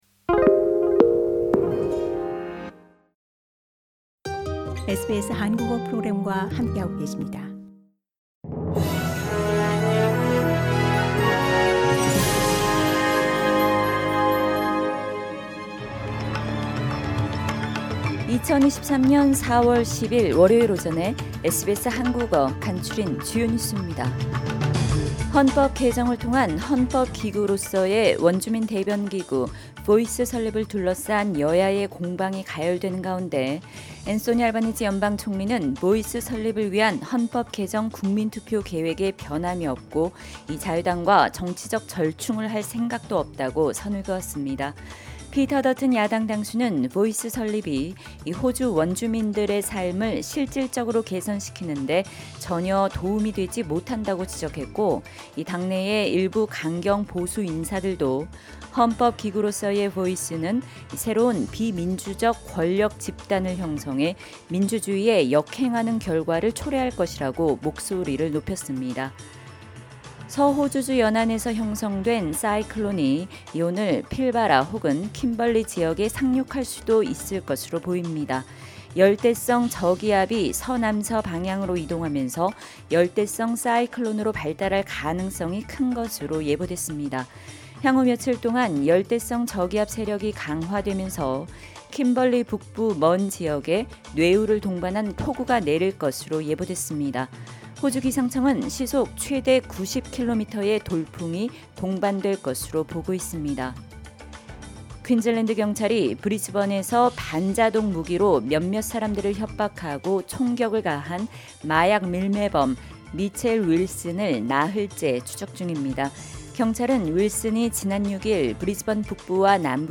SBS 한국어 아침 뉴스: 2023년 4월 10일 월요일